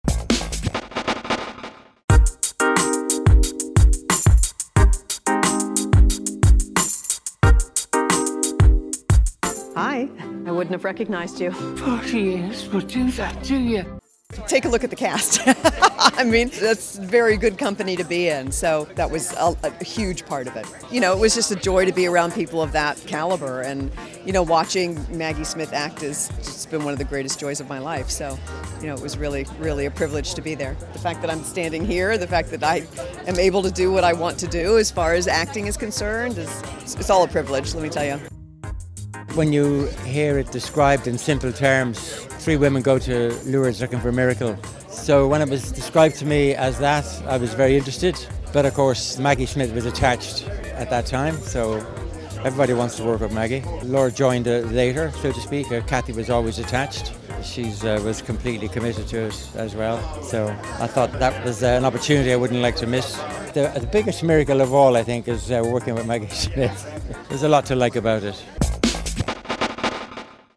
Laura Linney Praises Co-Star Maggie Smith at Tribeca Film Festival World Premiere of The Miracle Club
The Miracle Club star Laura Linney and director Thaddeus O’Sullivan sang the praises of Maggie Smith at the Tribeca Film Festival world premiere of the film in New York City.